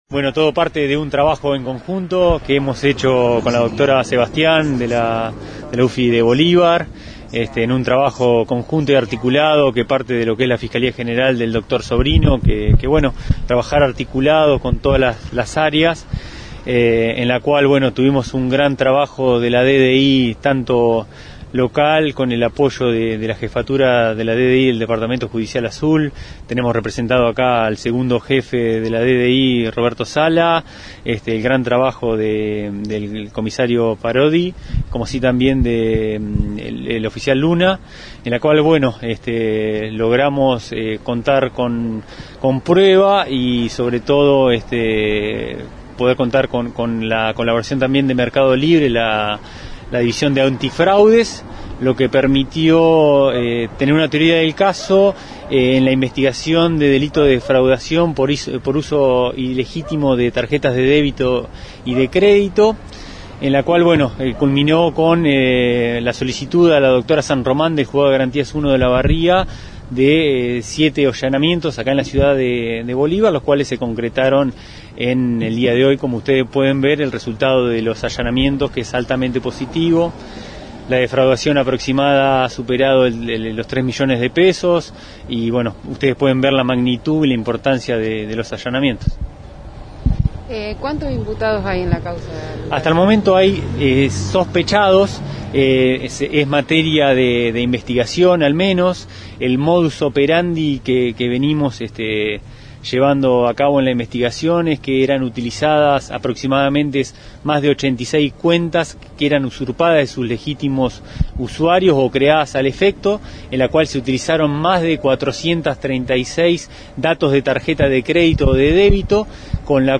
Fiscales Julia María Sebastian y Lucas Moyano